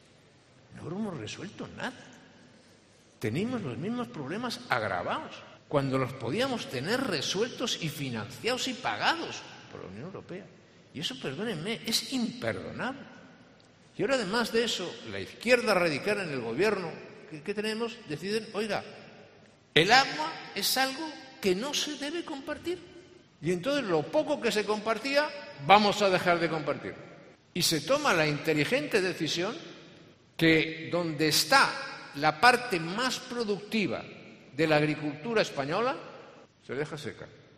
Aznar ha hecho estas declaraciones acompañado del presidente del Gobierno murciano y del PP regional, Fernando López Miras, en el diálogo económico titulado 'Región de Murcia-Espacio Libertad' que ha tenido lugar este viernes en el Casino de Murcia y que ha contado con la asistencia de distintas personalidades del ámbito empresarial y social de la Comunidad.